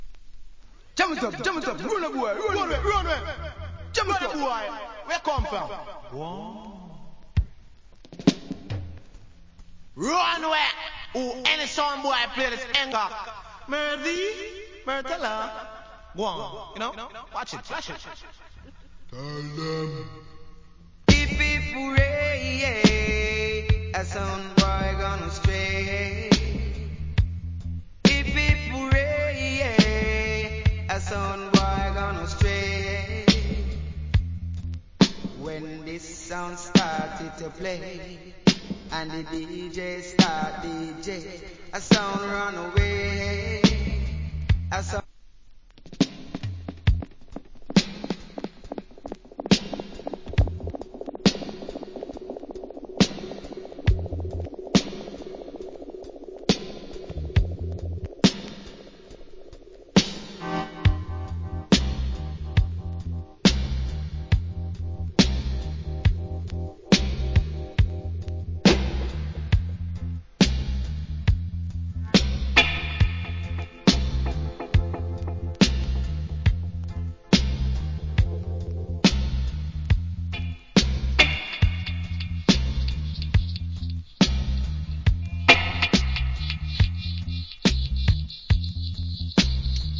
Killa Dancehall Vocal.